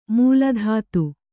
♪ mūla dhātu